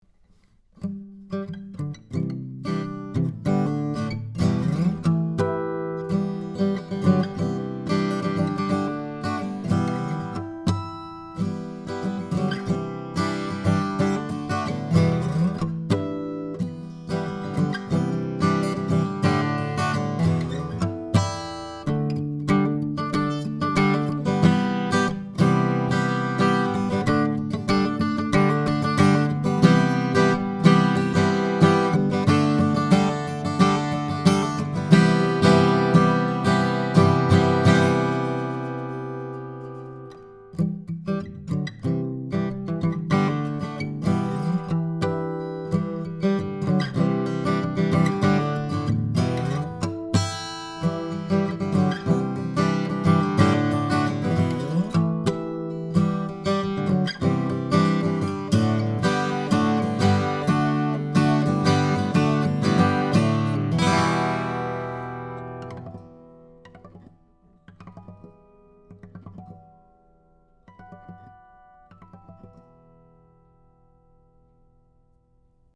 Here are several quick, 1-take MP3 sound files to give you an idea of what to expect. These MP3 files have no compression, EQ or reverb -- just straight signal, tracked with this Rode NT1-A mic into a TAB-Funkenwerk V78M preamp using a Sony PCM DI flash recorder.
SANTA CRUZ OM/PW GUITAR
OMPWNT1aV78D1Harmonic.mp3